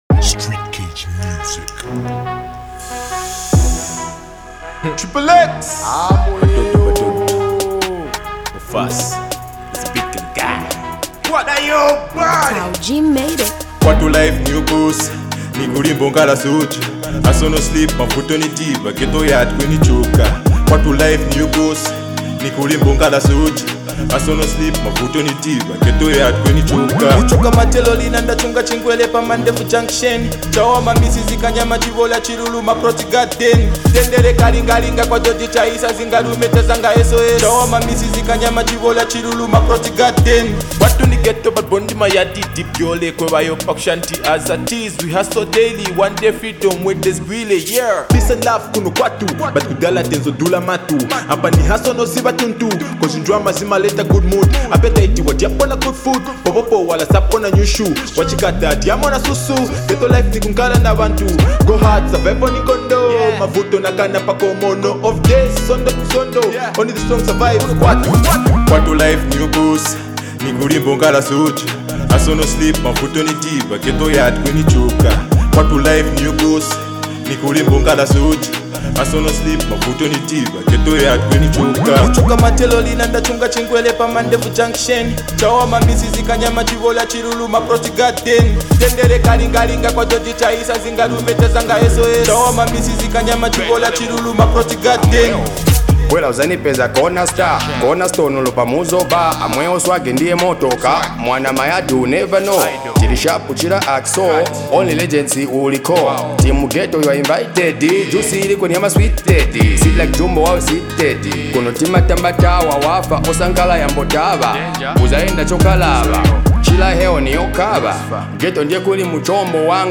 high-energy track that hits hard from the very first second